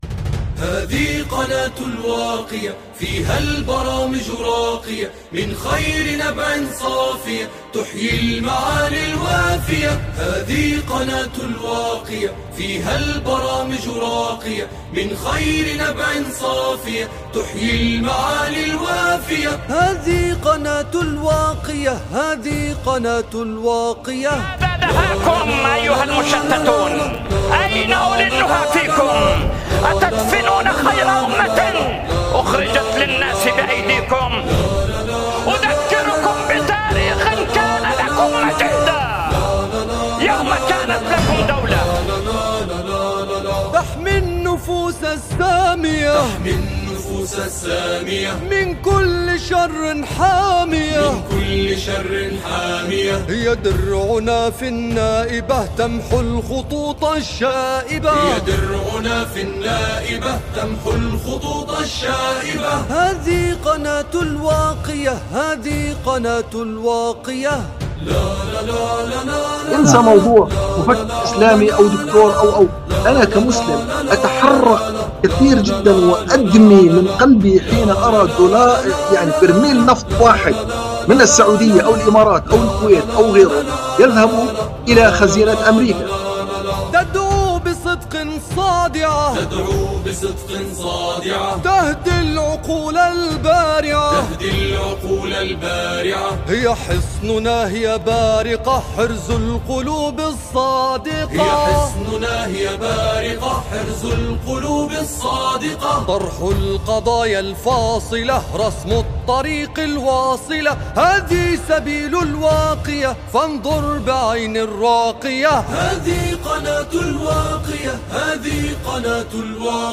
نشيد